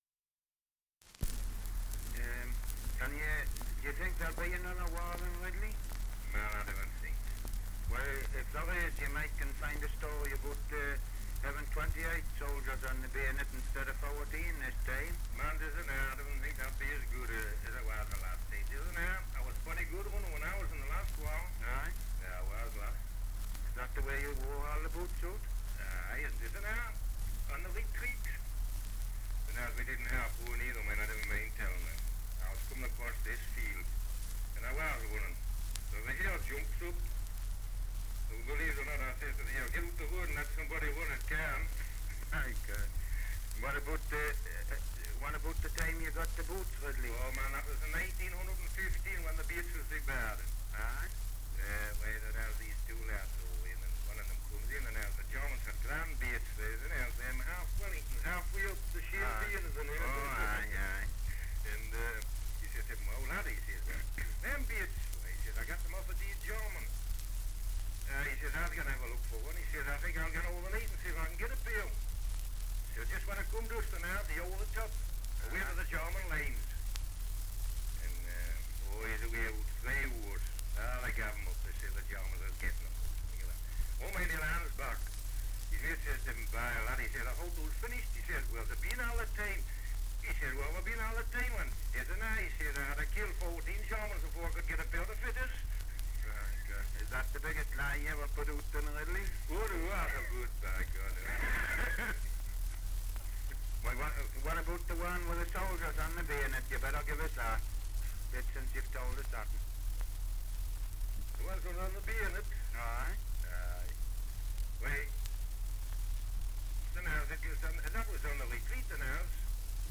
Dialect recording in Wall, Northumberland
78 r.p.m., cellulose nitrate on aluminium